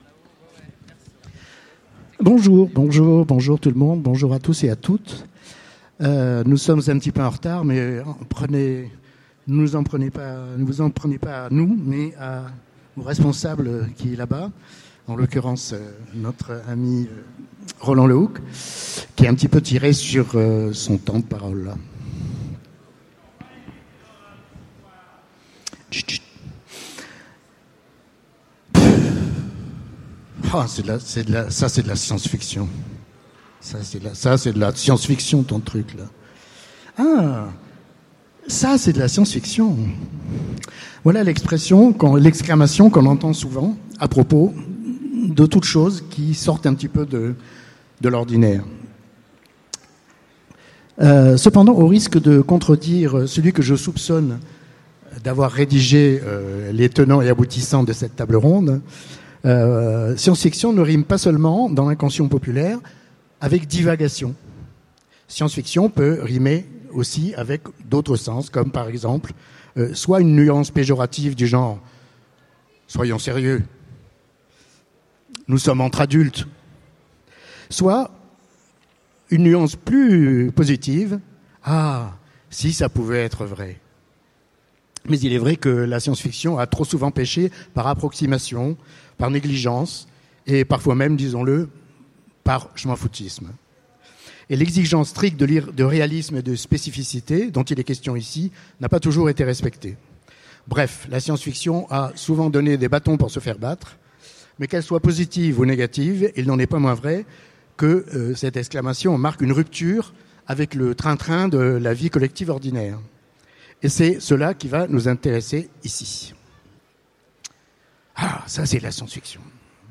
Utopiales 2015 : Conférence Mais ça, c’est de la science-fiction !